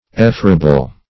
Effrayable \Ef*fray"a*ble\, a.